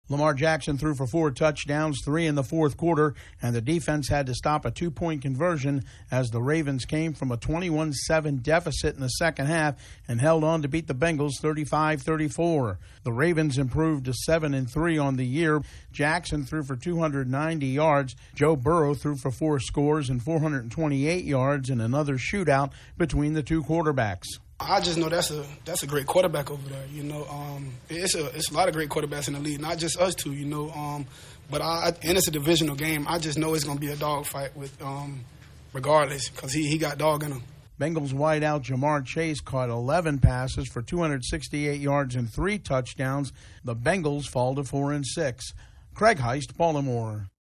Lamar Jackson shines once again in a win over the Bengals. correspondent